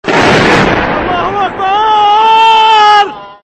sfx_hit.mp3